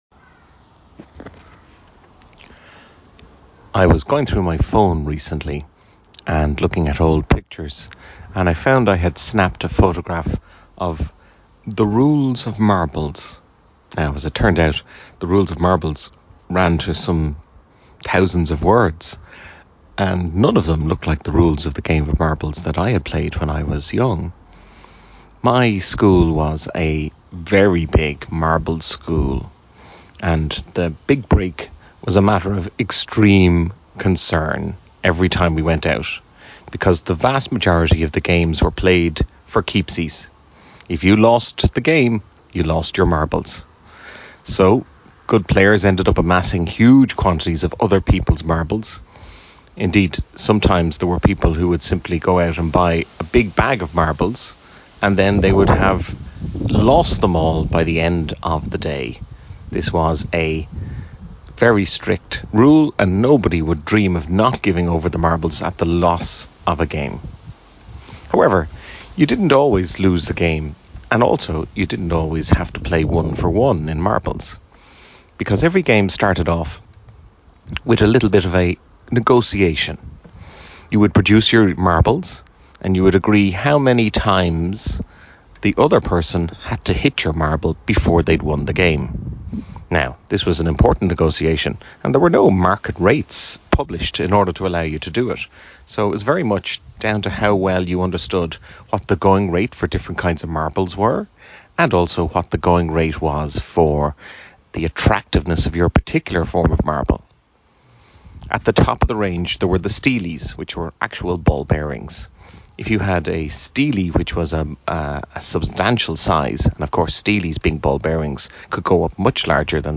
Sitting in the garden, thinking about marbles.